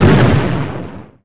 gen_small_explo_02.wav